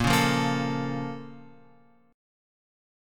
A#mM7 chord {6 4 3 3 x 5} chord